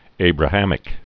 (ābrə-hămĭk)